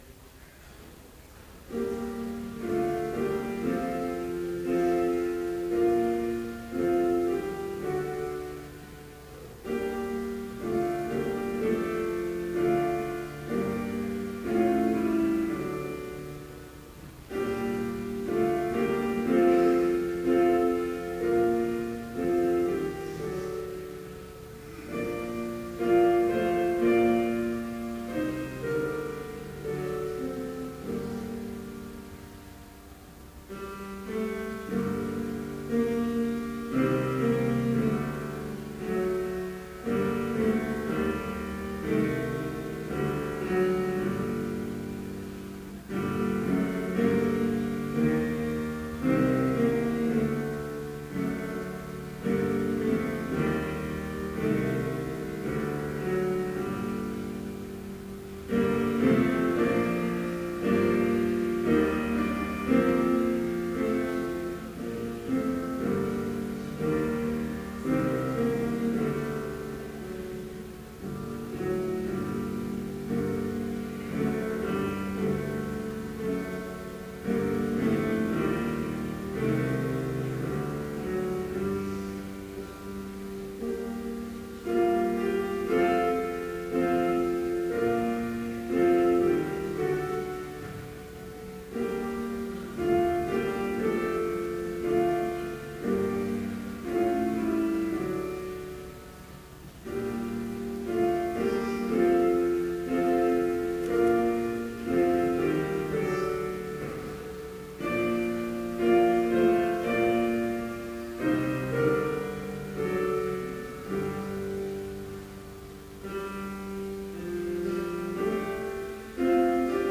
Complete service audio for Chapel - April 15, 2014